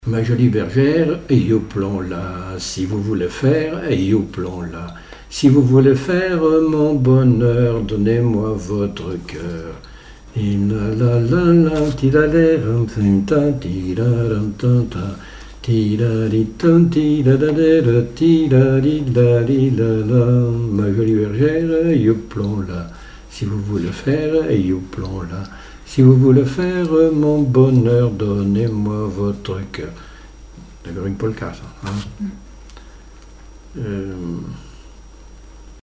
danse : polka
Chansons et témoignages
Pièce musicale inédite